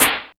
kits/RZA/Snares/WTC_SNR (14).wav at 32ed3054e8f0d31248a29e788f53465e3ccbe498